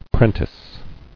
[pren·tice]